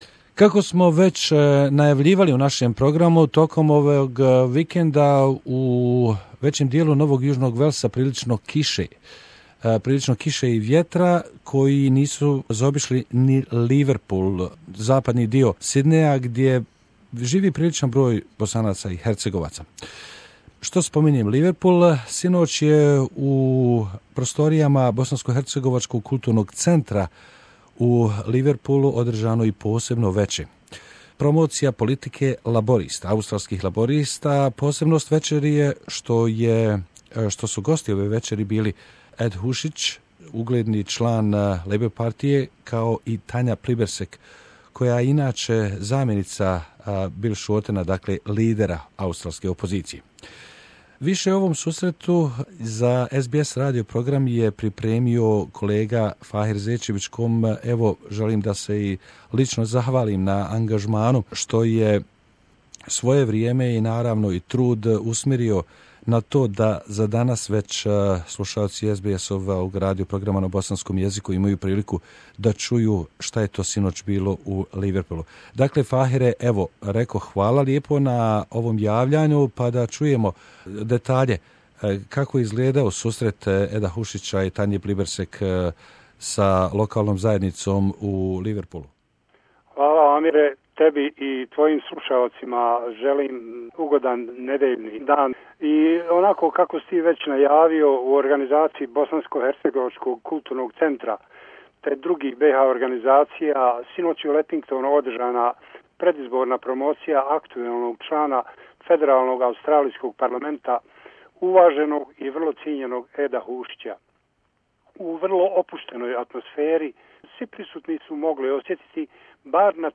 Tanya Plibersek MP i Ed Husic MP, ABHCA Leppington, NSW, June 4 2016